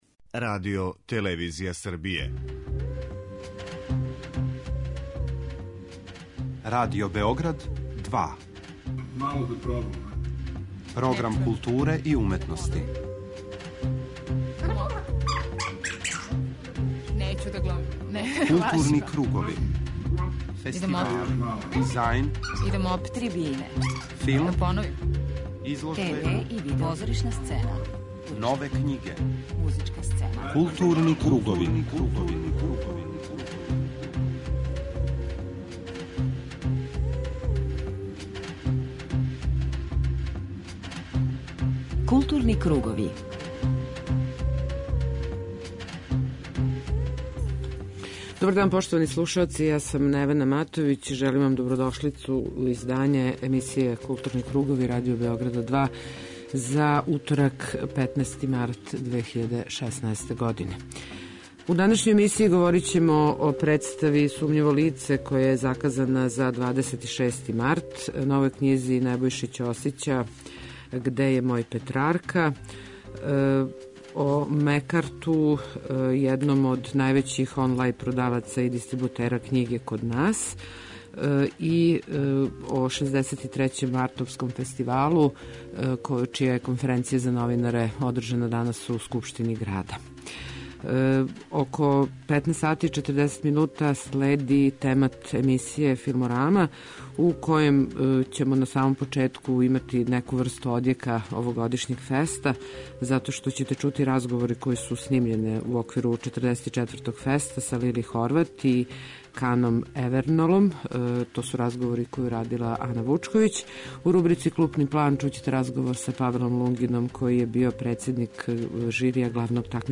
На почеку темата чућете звучну белешку са конференције за новинаре којом је најављен почетак 63. Београдског фестивала докуметарног и краткометражног филма. Затим следе разговори снимљени на 44. ФЕСТ-у.